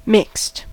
mixed: Wikimedia Commons US English Pronunciations
En-us-mixed.WAV